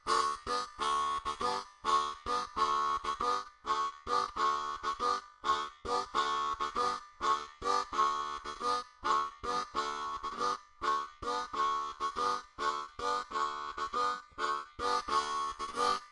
口琴练习剪辑 " 口琴节奏 08 ( 可循环 )
描述：这是一个演奏节奏裂缝的海洋乐队口琴的录音。
Tag: 口琴 节奏 钥匙 G